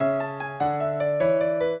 piano
minuet6-10.wav